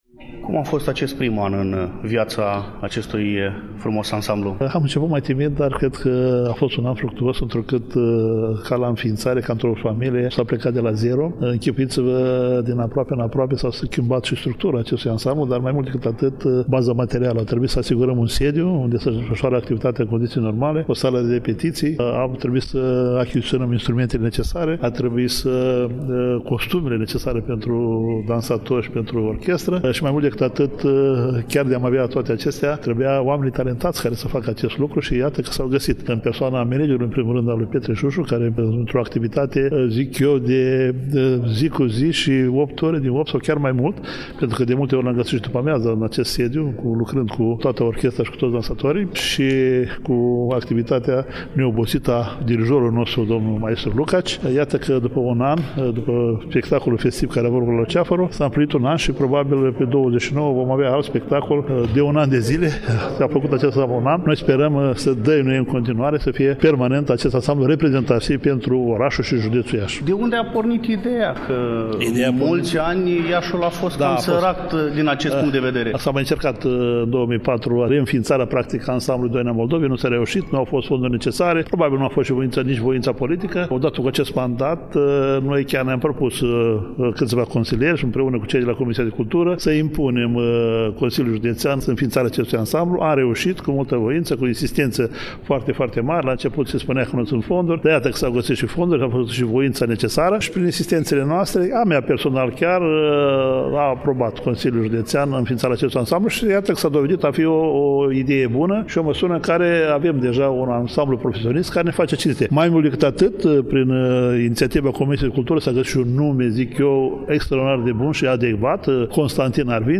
Despre activitatea și planurile ansamblului s-a făcut vorbire în conferința de presă (marți, 26 noiembrie 2019 ora 12:00), la sediul Ansamblului Artistic Profesionist ,,Constantin Arvinte” al Consiliului Județean Iași.
Victor Chirilă – vicepreședintele Consiliului Județean Iași